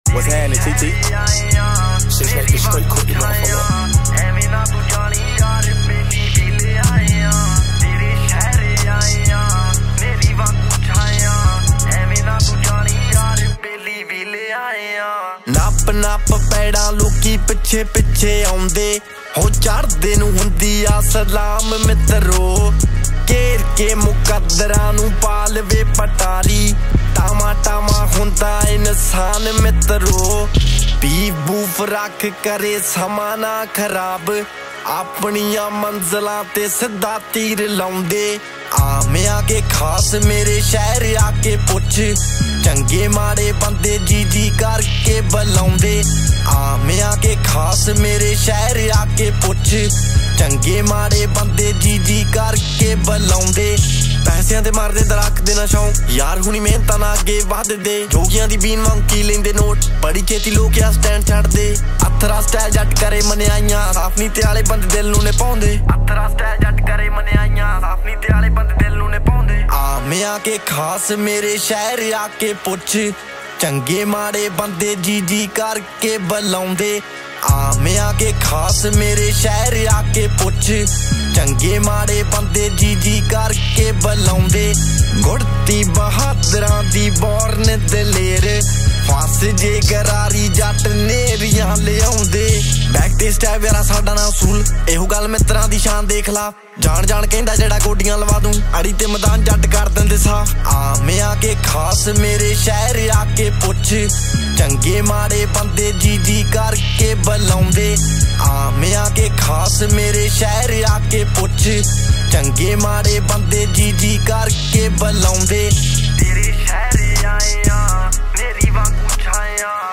Punjabi Single Track